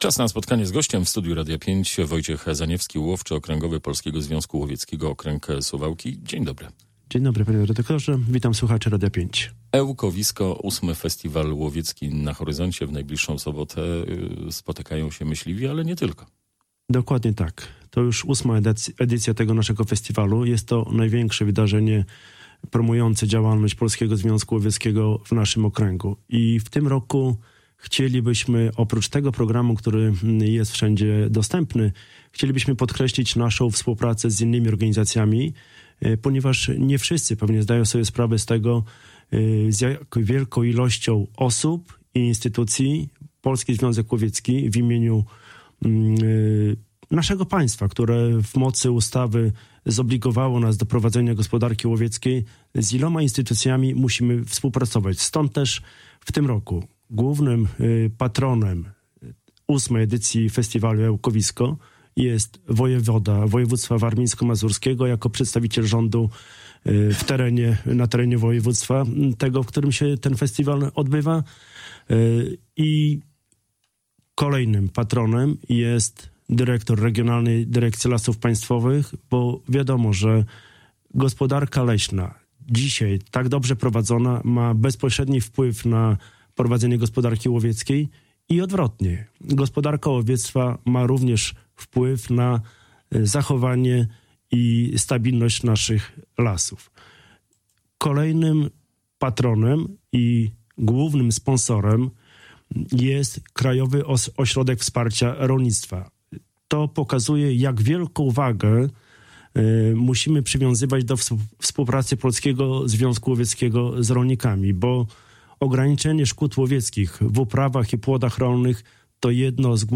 ROZMOWA-ELKOWISKO-CALA.mp3